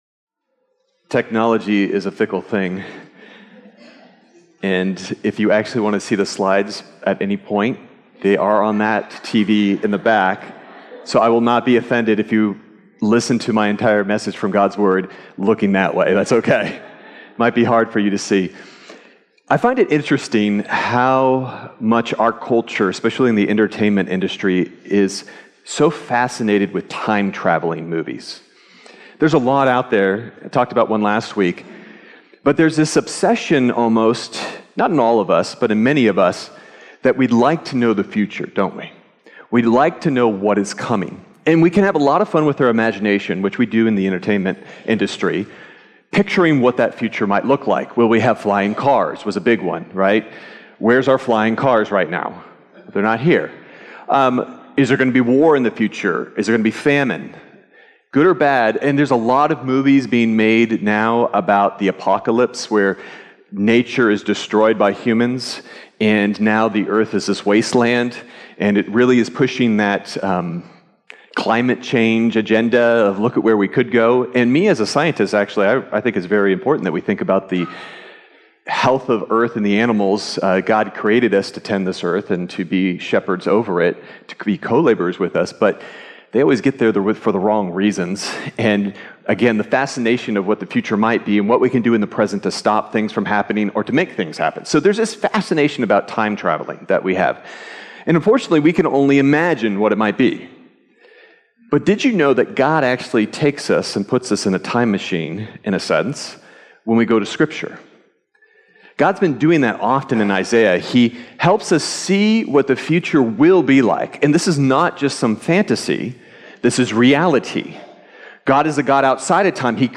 Sermon Detail
May_5th_Sermon_Audio.mp3